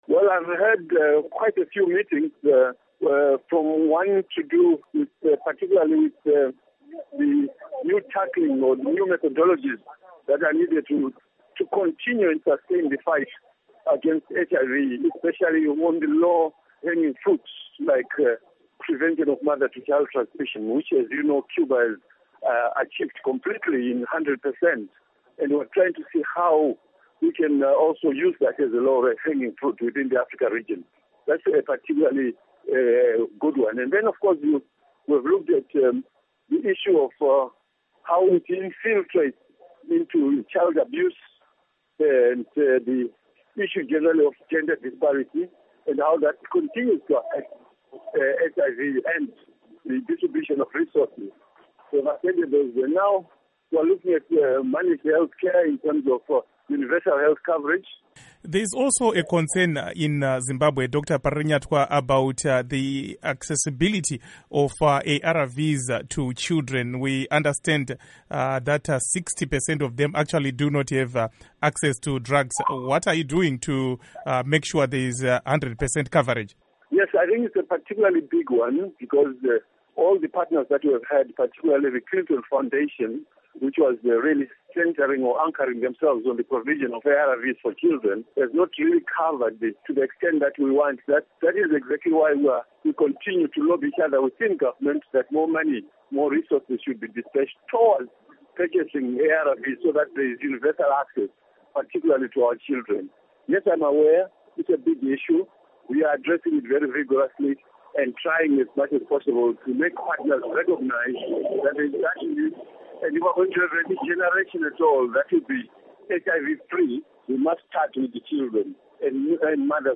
Interview With Dr. David Parirenyatwa